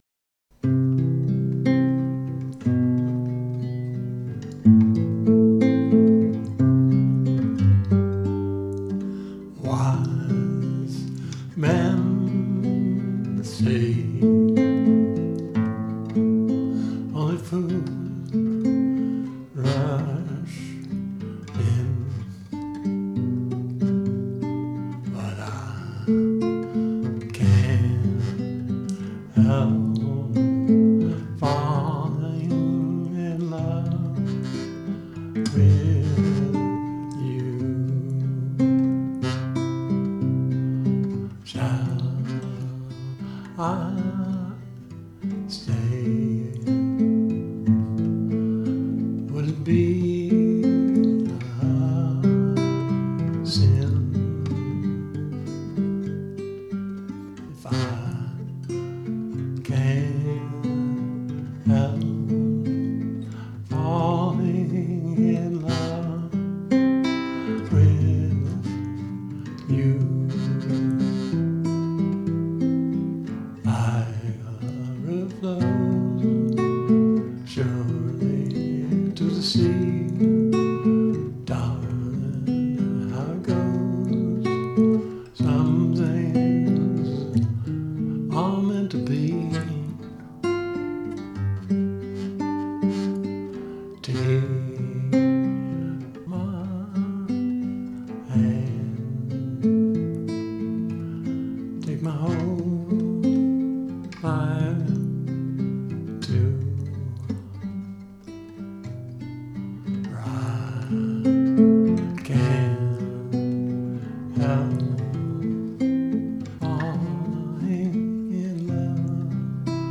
Standard Tuning [E,A,D,G,B,e]